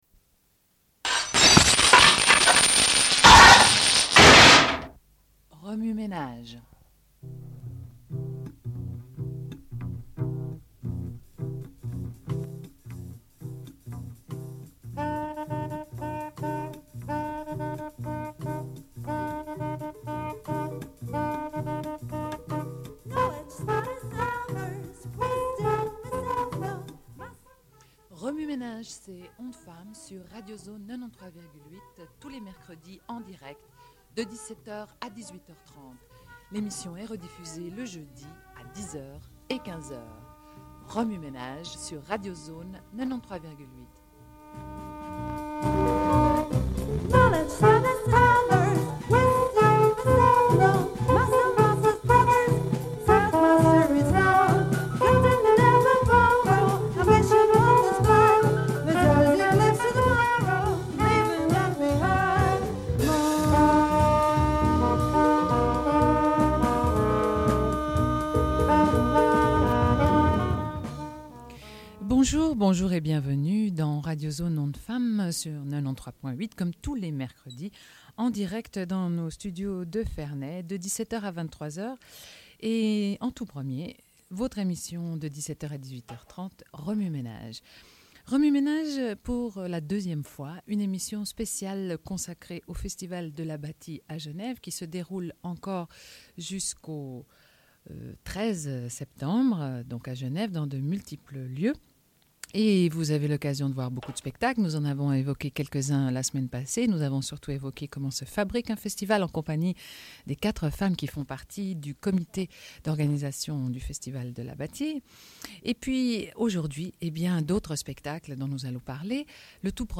Une cassette audio, face A31:53